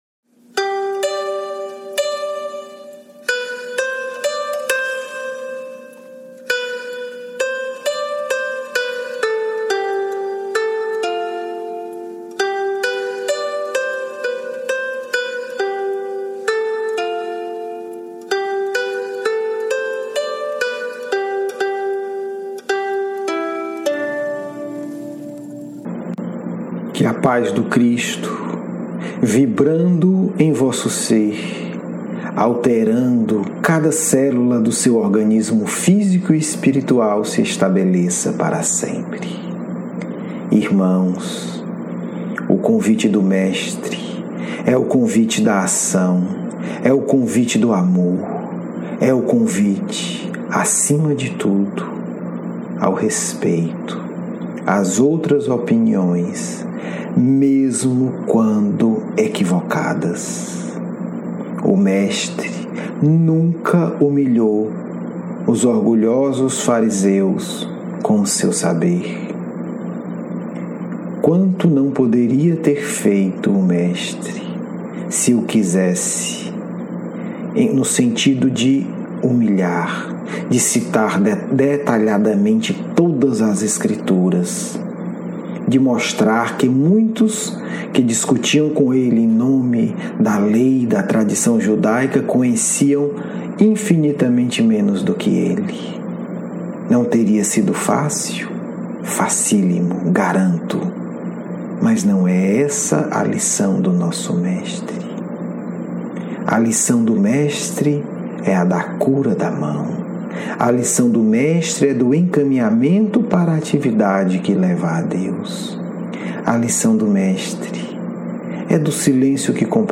Diálogo mediúnico